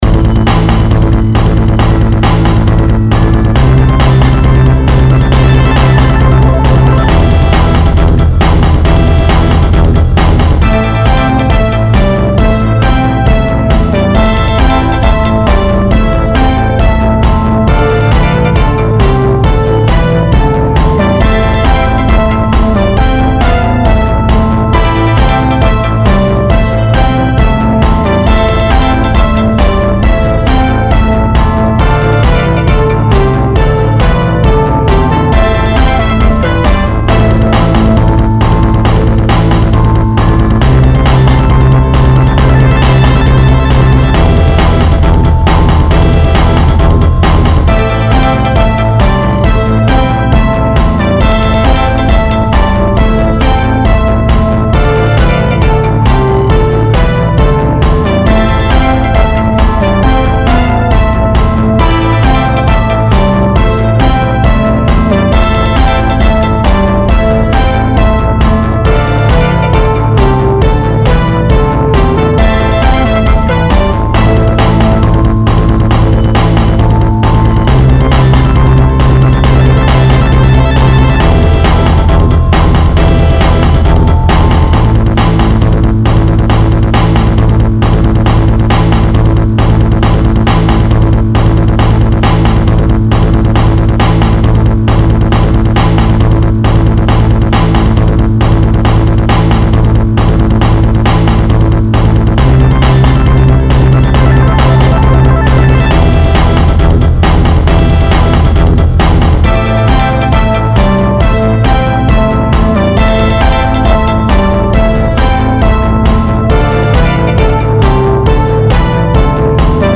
And this is a headphone mix . . . ]